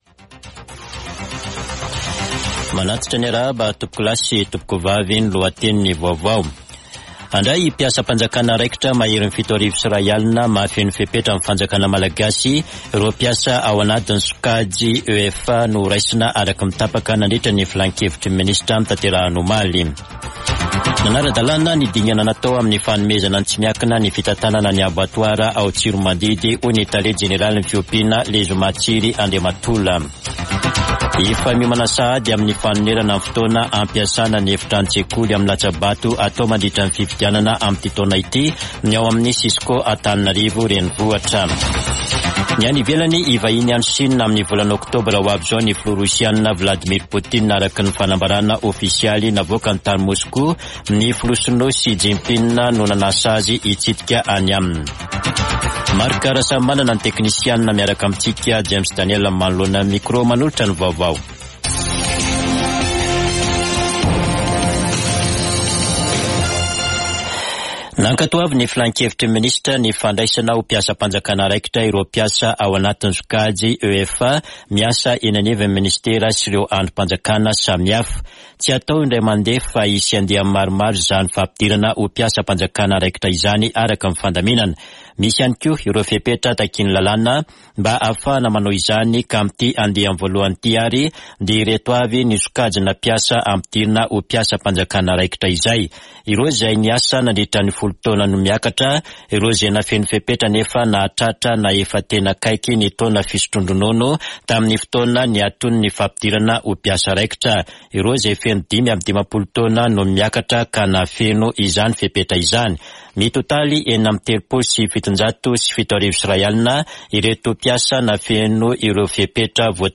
[Vaovao antoandro] Alakamisy 21 septambra 2023